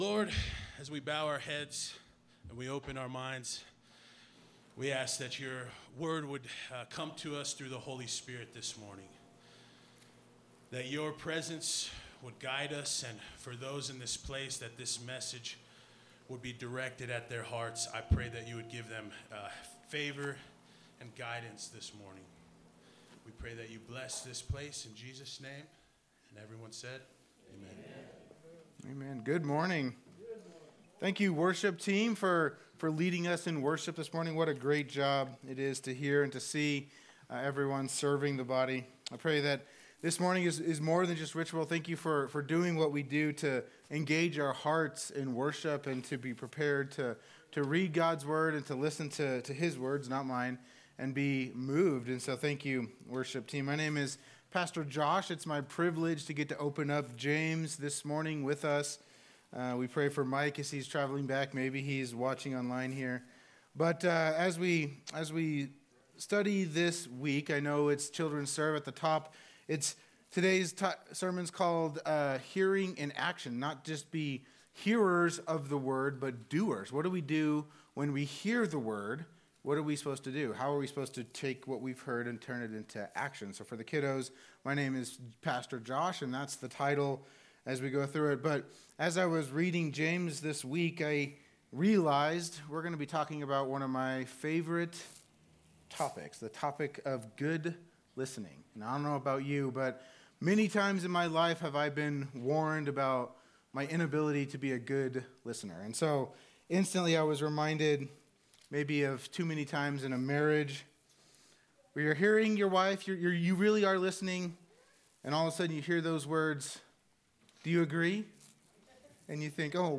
Location: High Plains Harvest Church